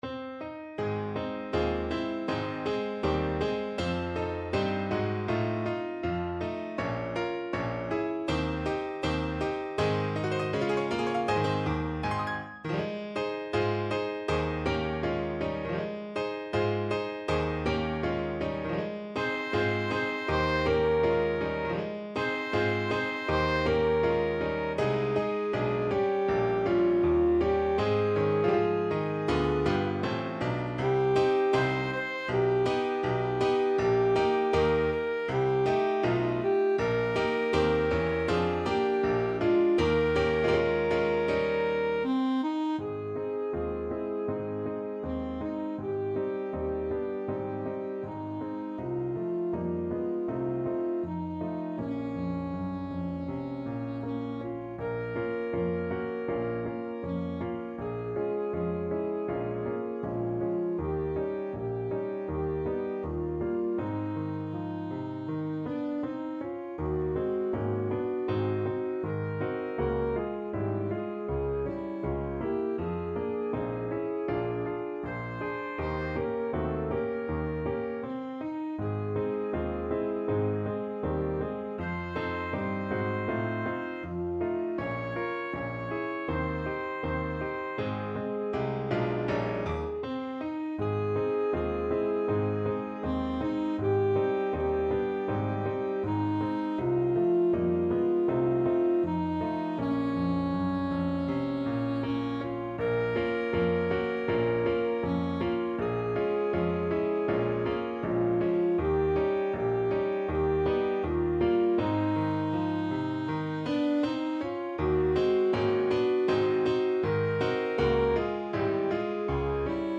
Alto Saxophone
2/2 (View more 2/2 Music)
Moderato =80
Pop (View more Pop Saxophone Music)